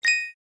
coin_catch.mp3